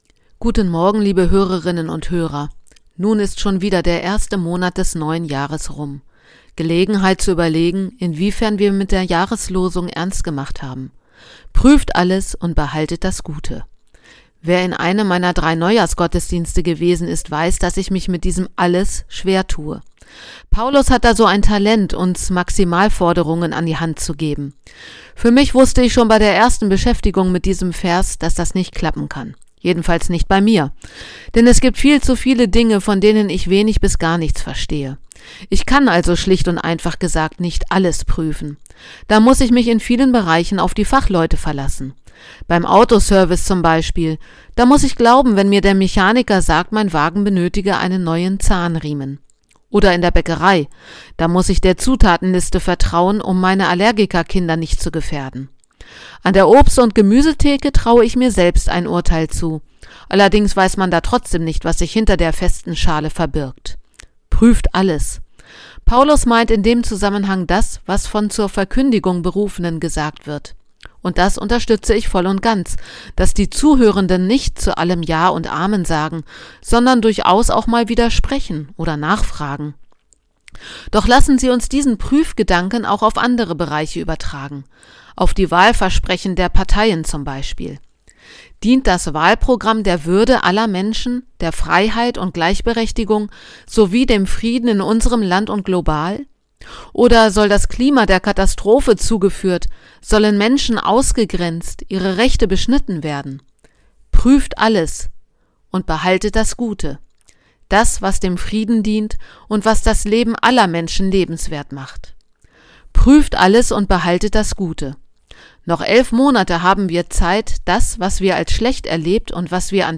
Radioandacht vom 31. Januar